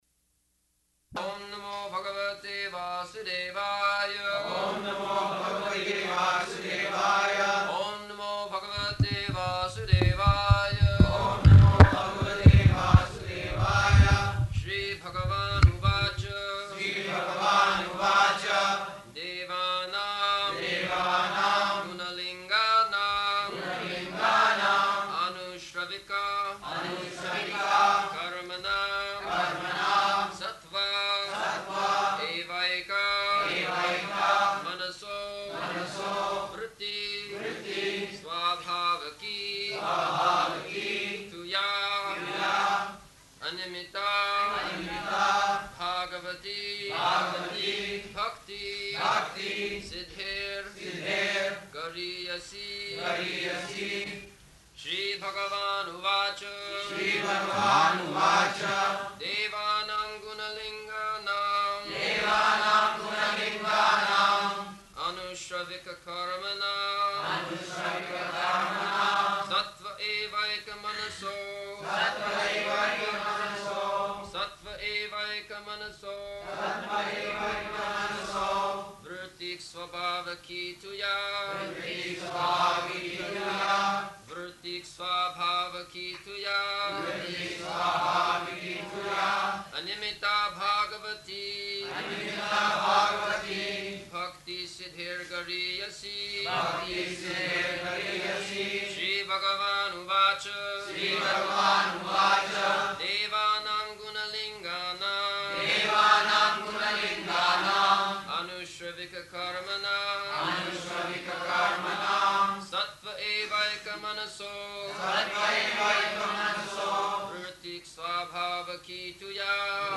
December 2nd 1974 Location: Bombay Audio file
[devotees repeat] [leads chanting of verse, etc.]